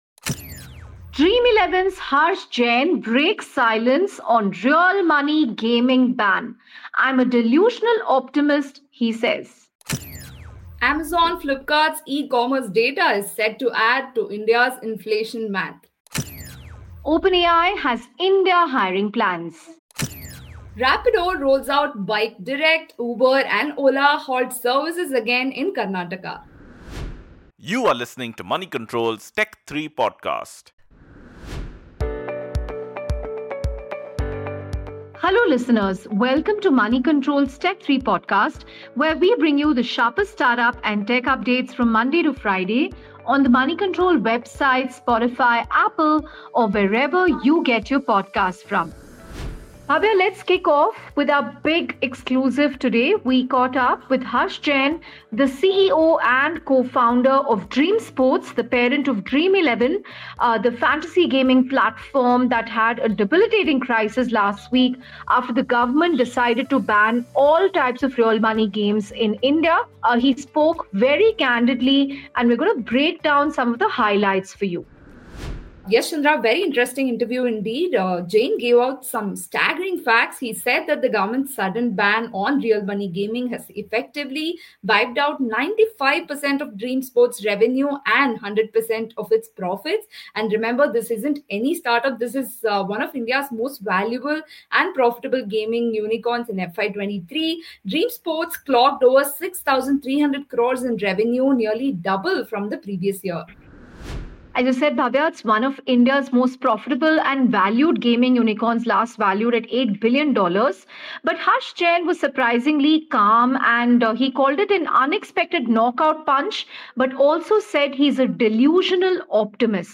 In today’s Tech3 from Moneycontrol, we bring you Harsh Jain’s exclusive interview with us on the government’s real-money gaming ban that wiped out 95% of Dream11’s revenue, and how the company plans to pursue more sports opportunities with the help of AI. We also decode how Amazon and Flipkart data will soon be part of India’s inflation calculations, and why OpenAI is betting big on India with a fresh hiring drive.